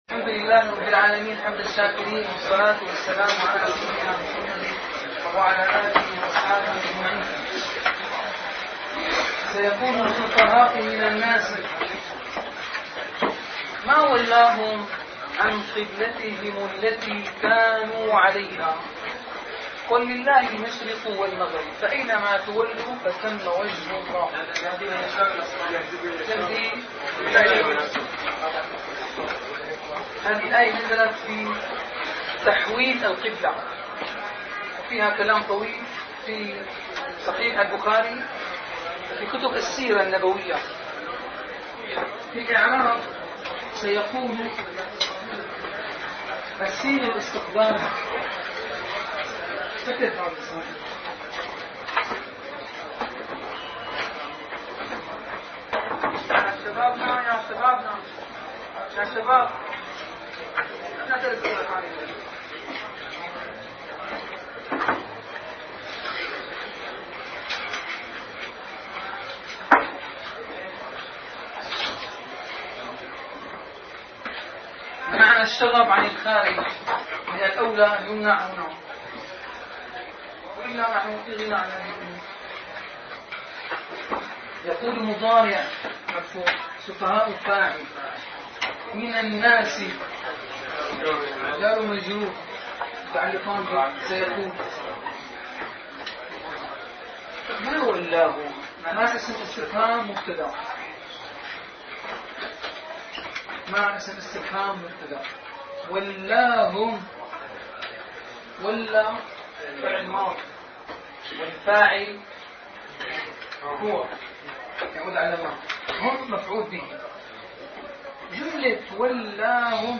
- الدروس العلمية - دورة مفصلة في النحو والإعراب والصرف - الإعراب - الدرس الثلاثون